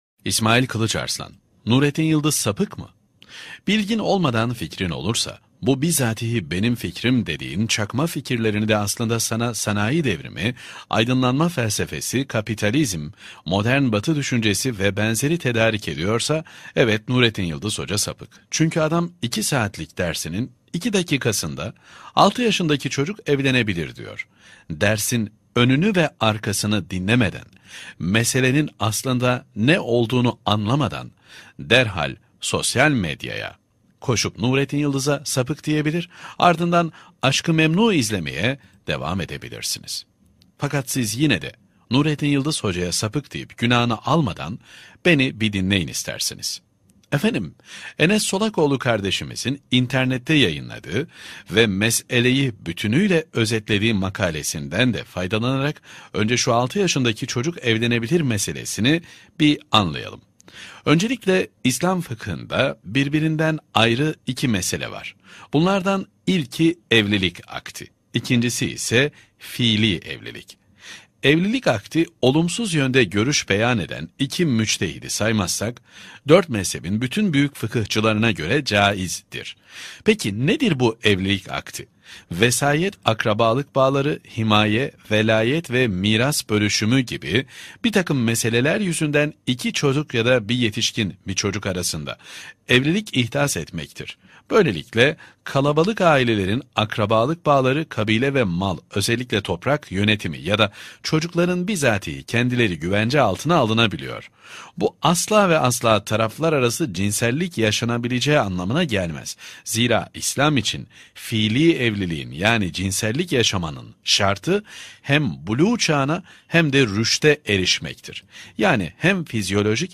2. Sesli Makale